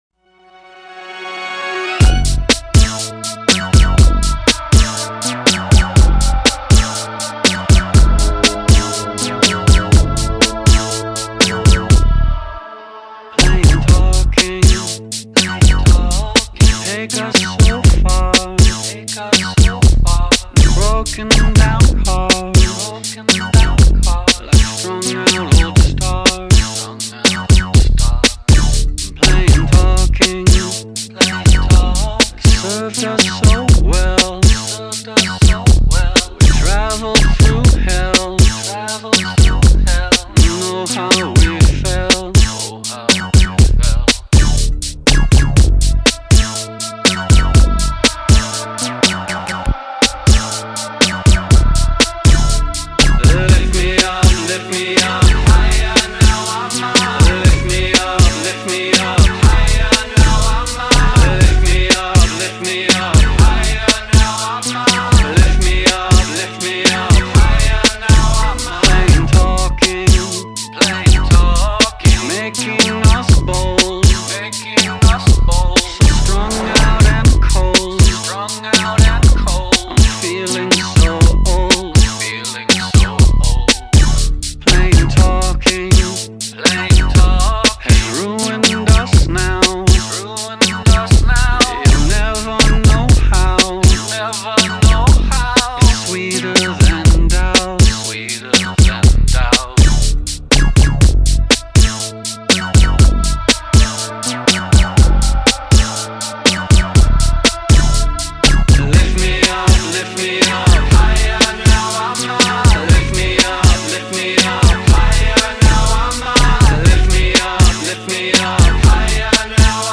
all mashups